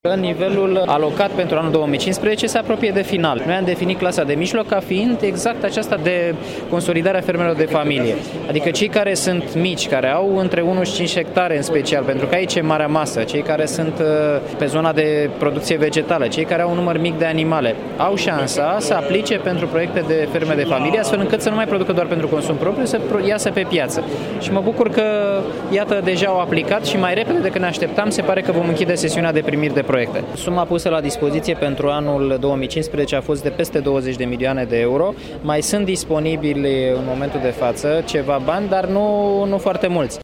Programul pentru modernizarea fermelor de familie se bucură de un real succes, a declarat, astăzi, la Timişoara ministrul agriculturii Daniel Constantin.
Daniel Constantin a subliniat că programul vizează consolidarea clasei de mijloc şi că acest lucru este important pentru dezvoltarea agriculturii: